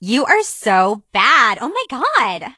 emz_kill_vo_02.ogg